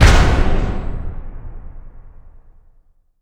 LC IMP SLAM 1.WAV